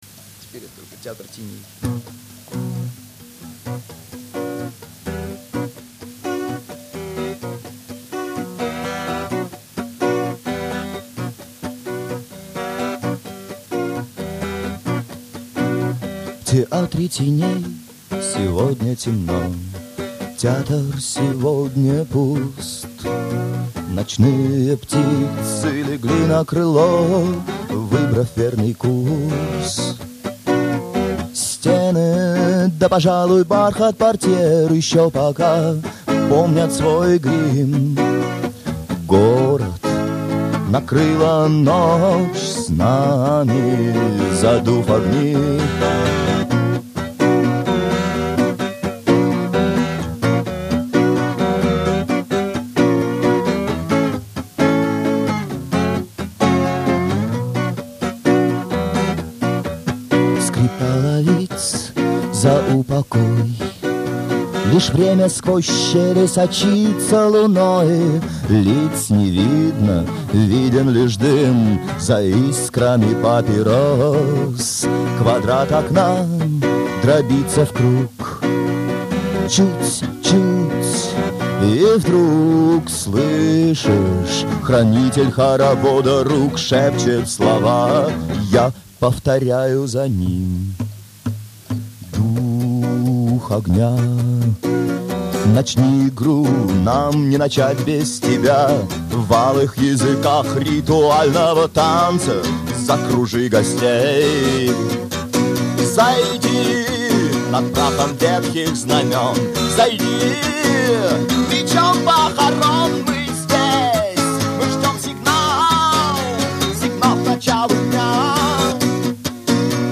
г.Новосибирск (2002)